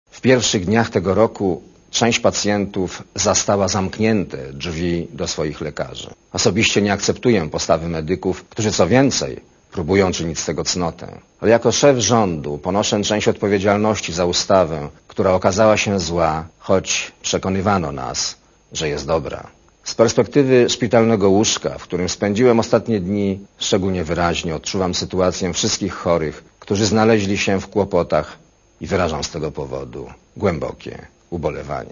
Premier przedstawił wieczorem w telewizyjnym wystąpieniu zadania rządu na 2004 r.
Premier Leszek Miller o zdrowiu (124 KB)